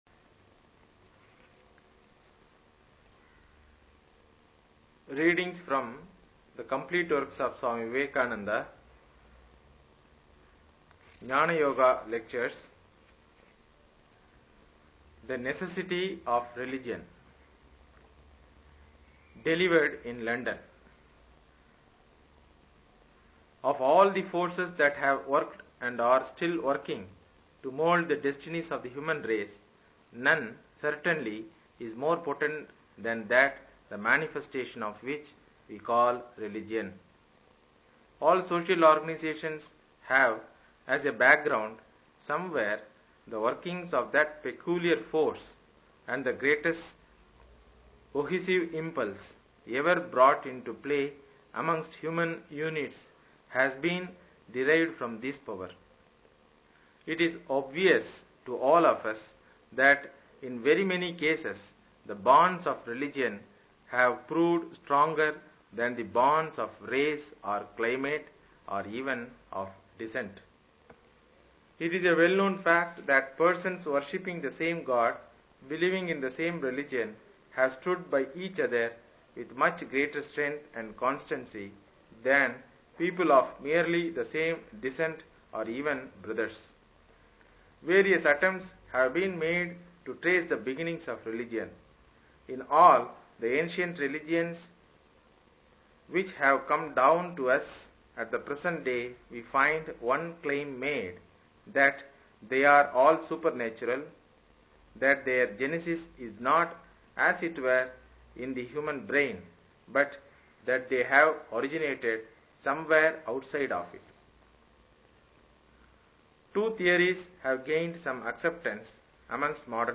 Readings from The Complete works of Swami Vivekananda